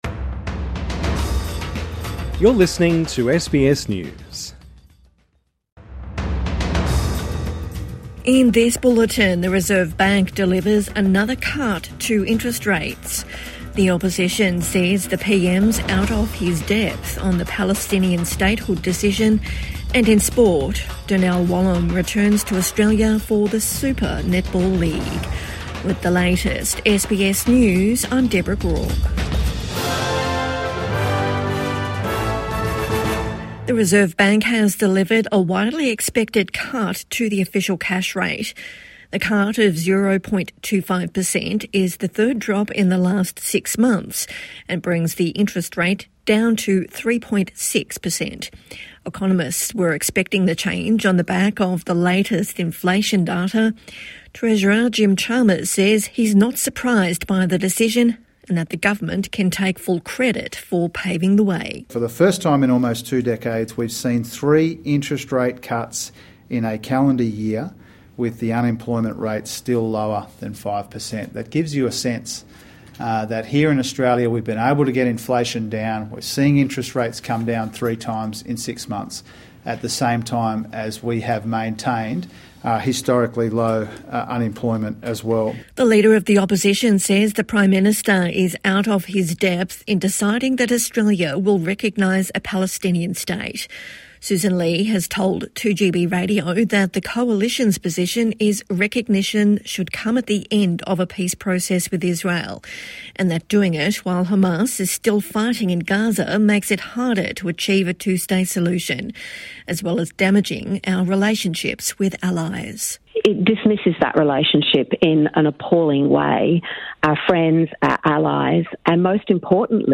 Reserve Bank cuts interest rates as expected | Evening News Bulletin 12 August 2025